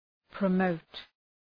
Προφορά
{prə’məʋt}